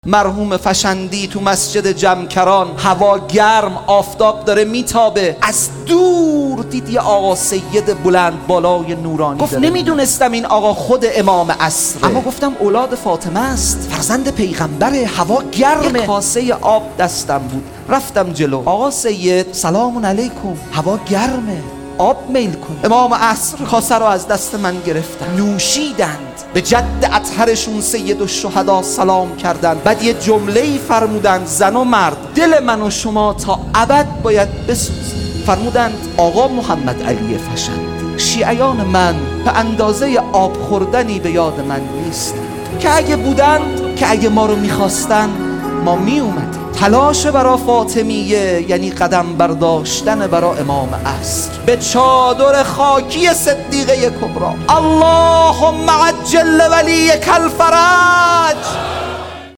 ایام فاطمیه 1441